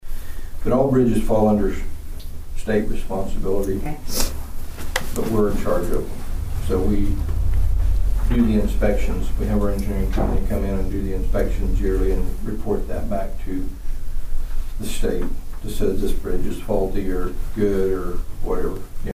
Commissioner Friddle further explains the role County Commissioners play in bridge production.